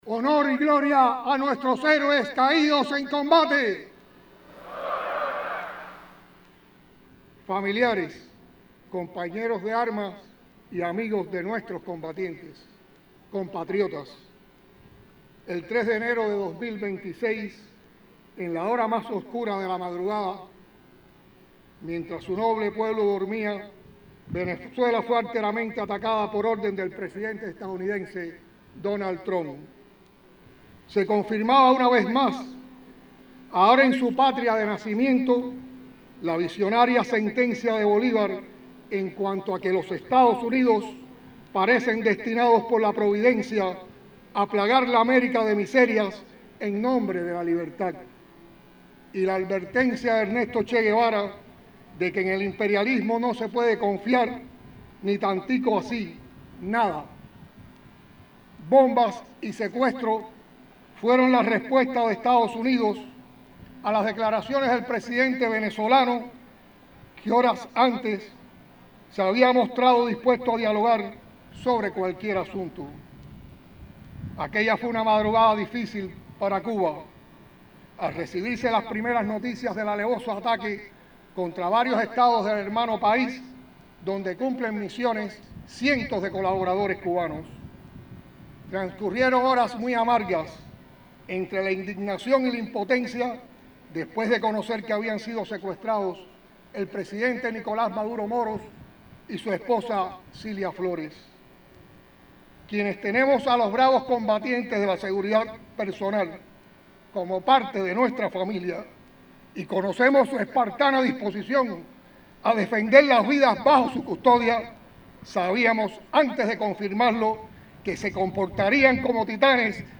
Miguel Díaz-Canel Bermúdez, Presidente de la República, pronunció las palabras centrales de la concentración de habaneros que en representación del pueblo de Cuba, reafirman la lucha por la justicia y la dignidad.